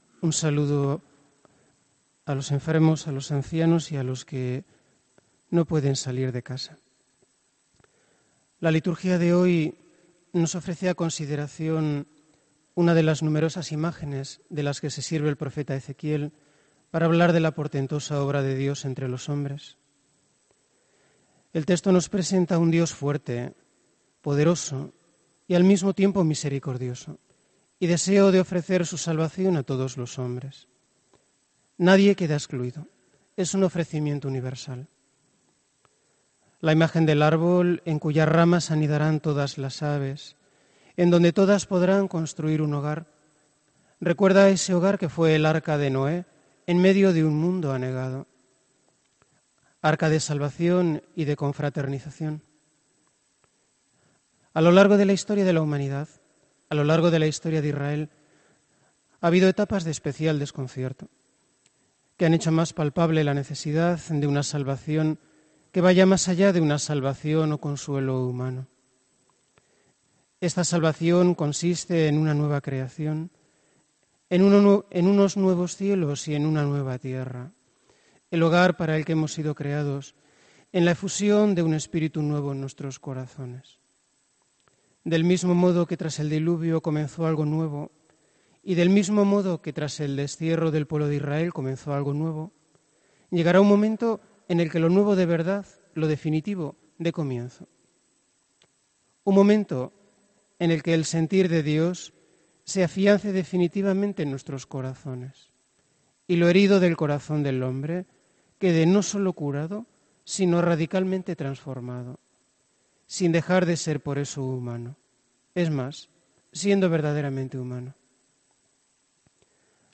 HOMILÍA 17 JUNIO 2018